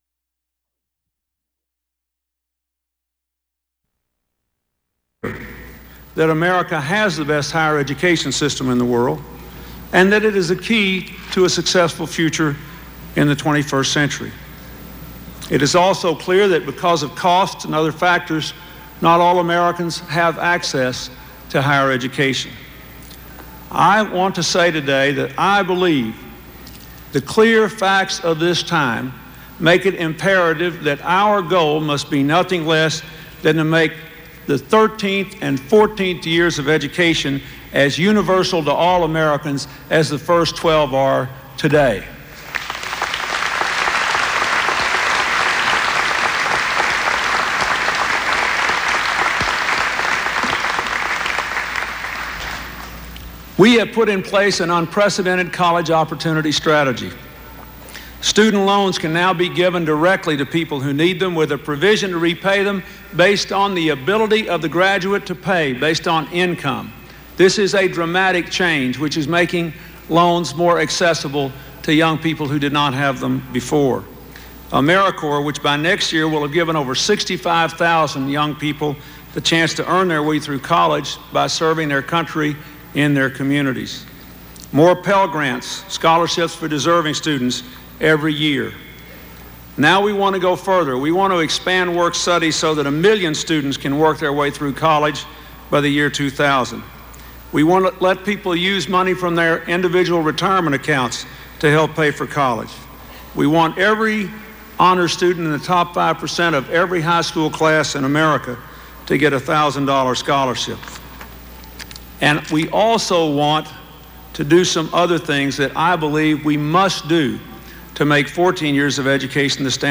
President Clinton proposes a universal $1,500 annual grant to all Americans who want two years of college. This is an excerpt from his commencement address at Princeton University.
Subjects Junior college students Student aid--Government policy United States Material Type Sound recordings Language English Extent 00:06:29 Venue Note Broadcast on PBS Newshour, June 4, 1996.